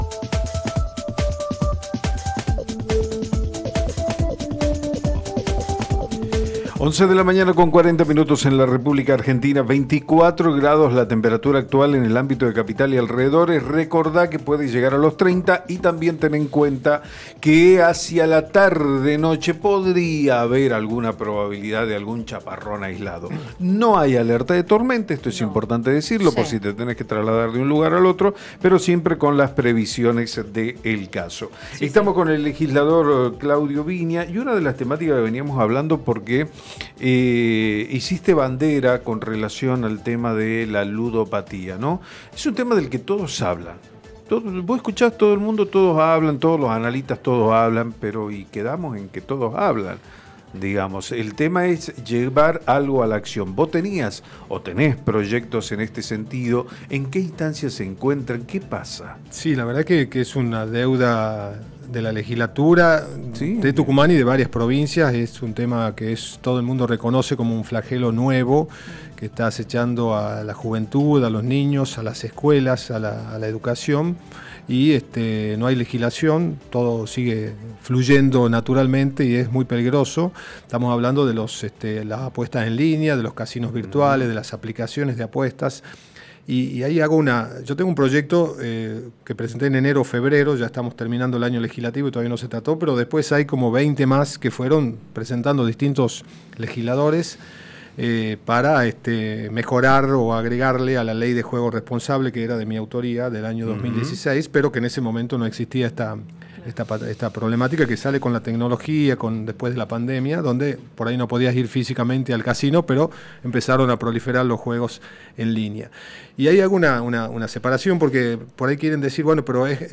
El legislador provincial Claudio Viña en diálogo con Café Prensa se refirió al proyecto del Presupuesto 2025.